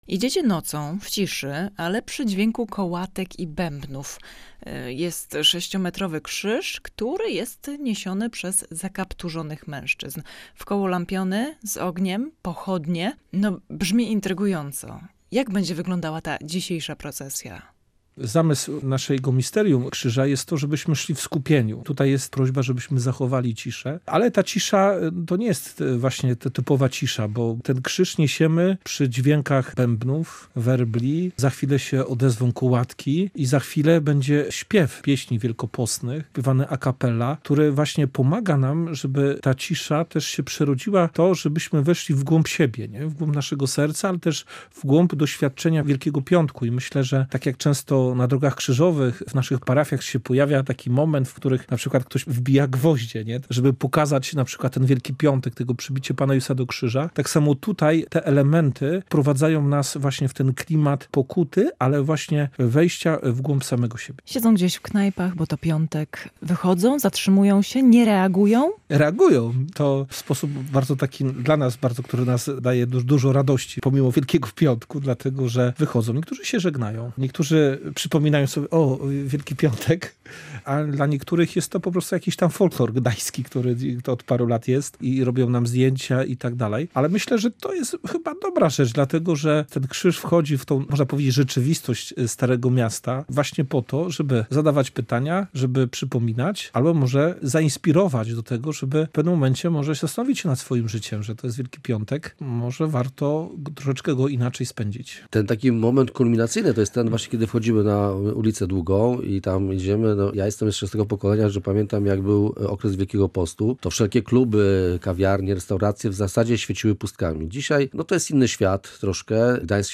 Idzie się nocą w ciszy, ale przy dźwięku kołatek i bębnów.